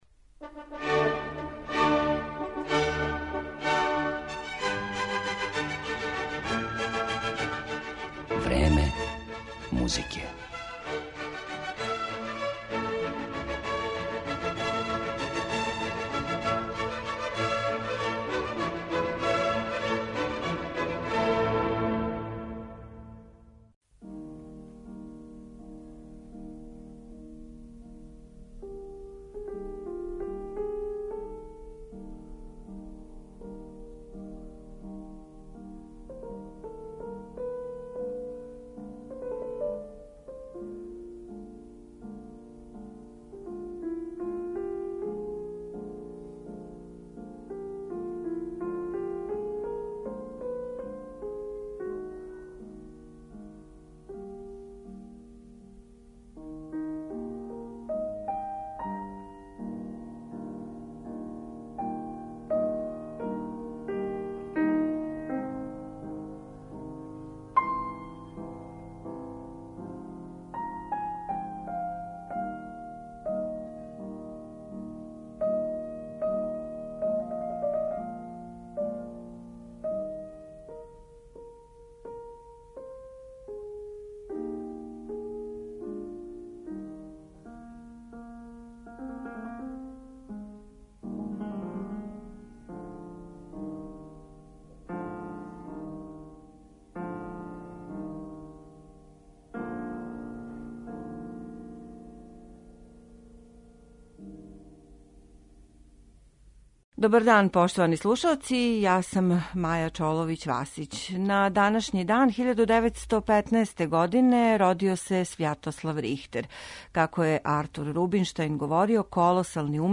Легендарни пијаниста Свјатослав Рихтер био је уметник који се није могао поредити ни са једним другим и кога су често и новинари и колеге - водећи уметници 20. века - називали најбољим пијанистом света.